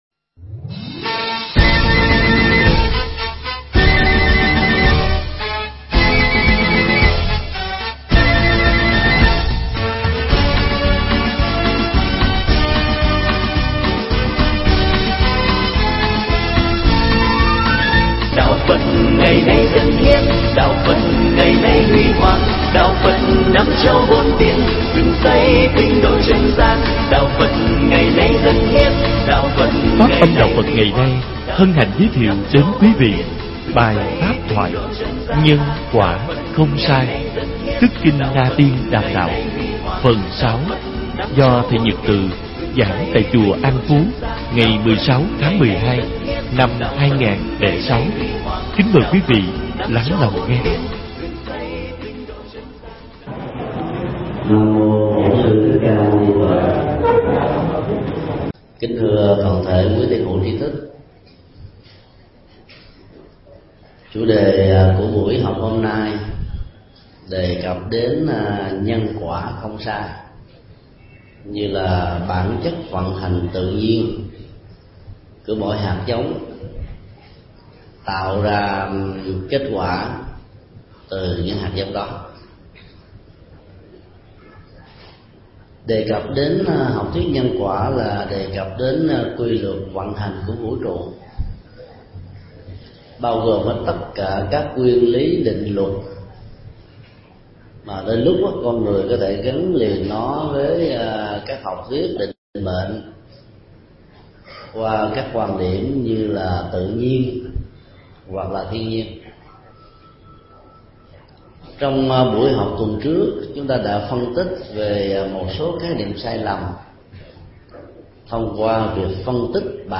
Tải mp3 Thầy Thích Nhật Từ thuyết pháp Nhân quả không sai – Phần 1/2 – tại Chùa An Phú, 16 tháng 12 năm 2006.